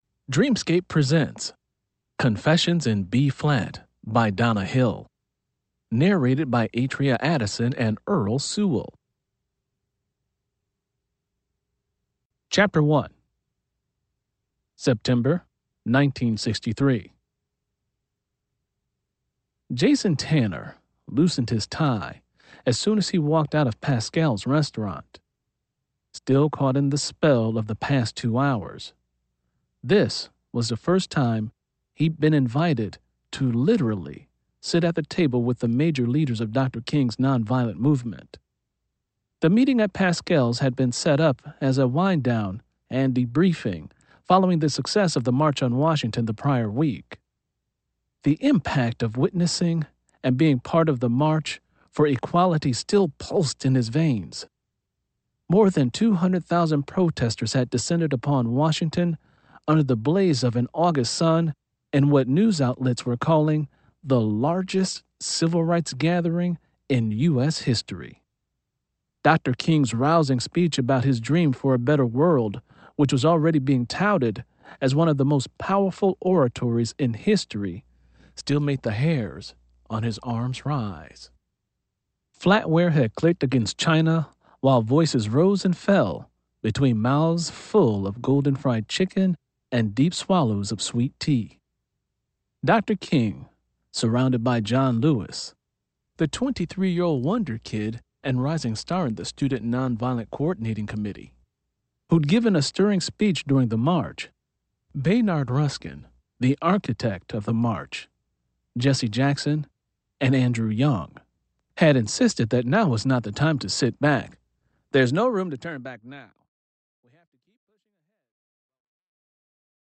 Eaudiobook
Edition: Unabridged.